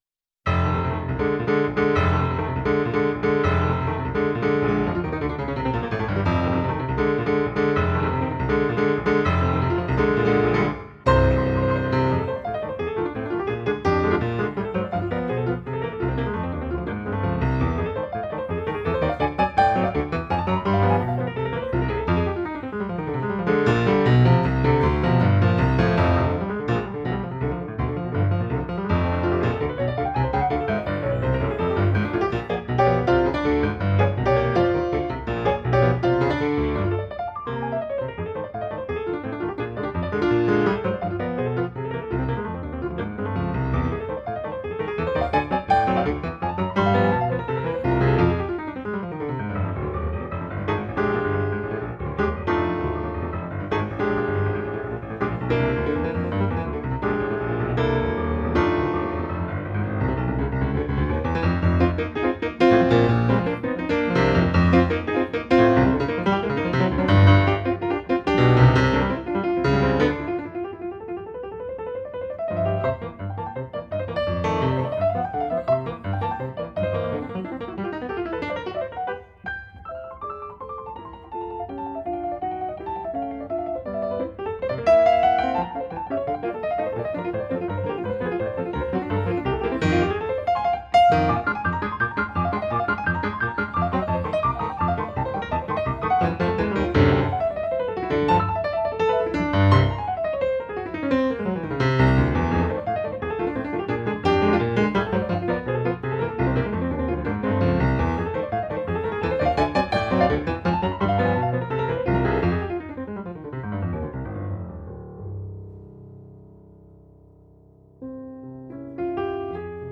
Jazzes composed for piano